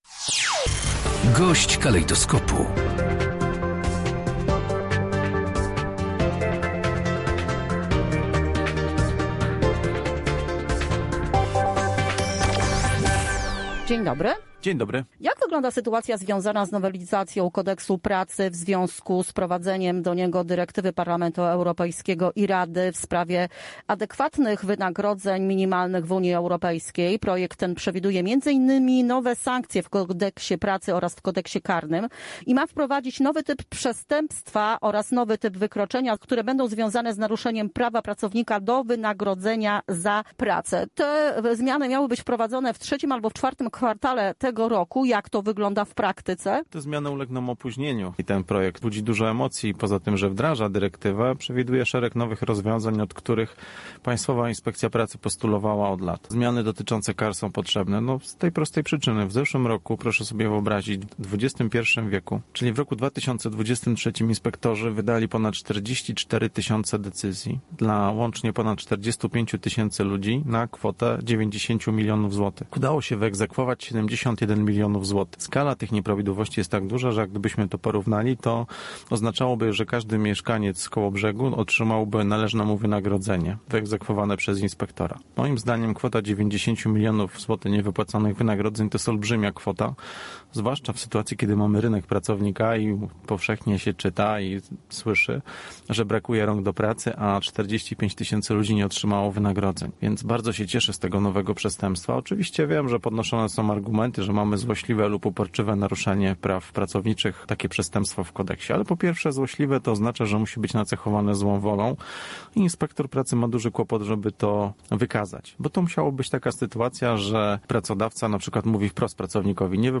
Marcin Stanecki Główny Inspektor Pracy powiedział na naszej antenie, że skala tego procederu jest ogromna.